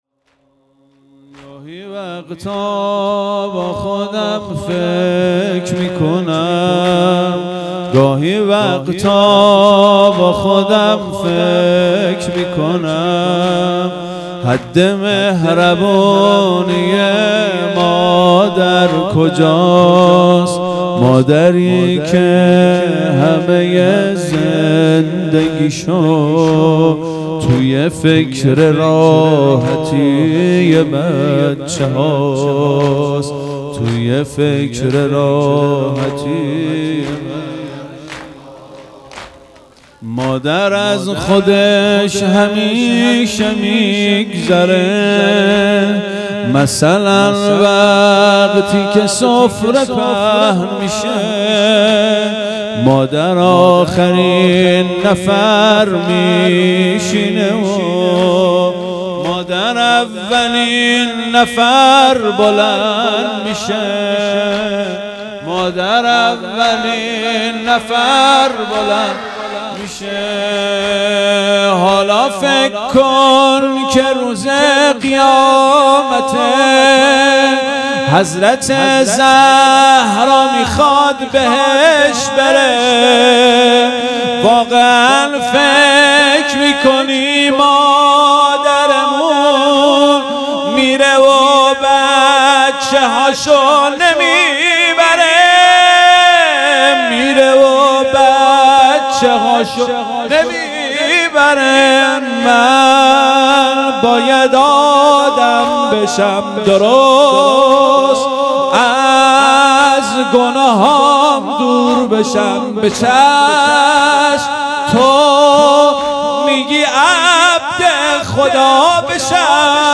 هیئت مکتب الزهرا(س)دارالعباده یزد